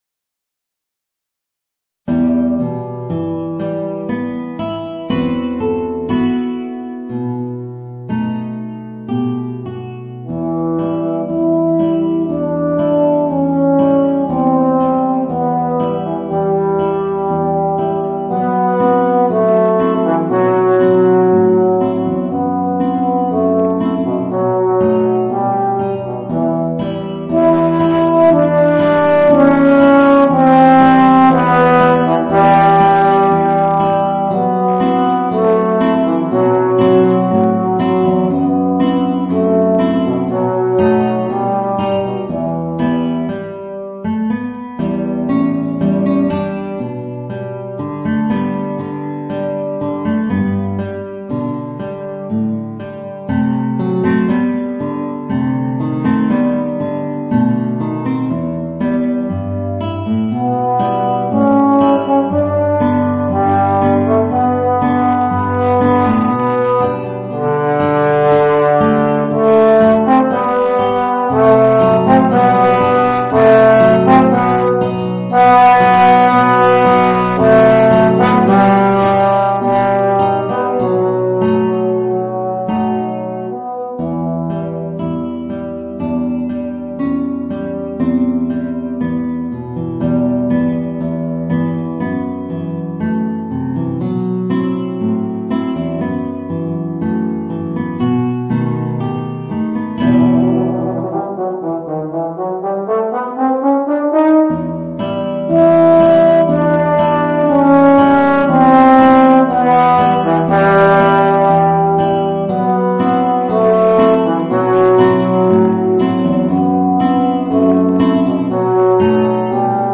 Voicing: Guitar and Euphonium